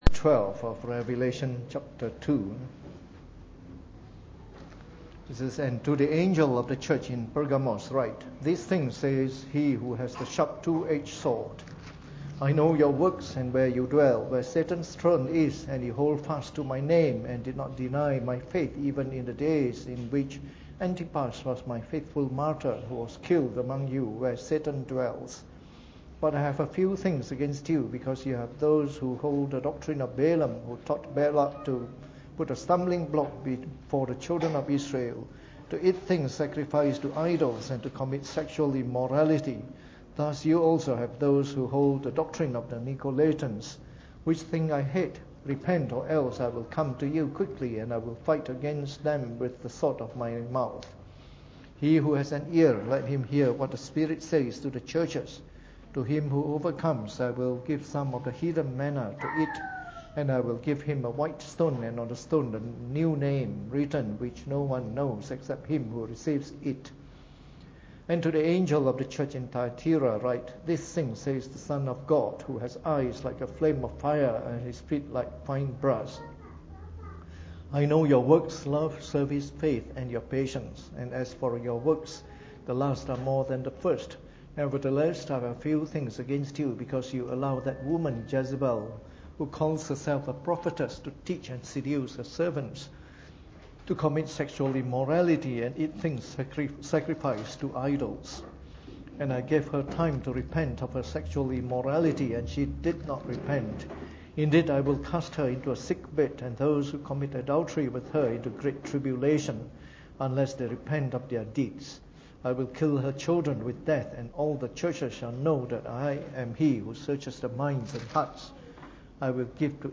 Preached on the 8th of March 2017 during the Bible Study, from our series on Semper Reformanda.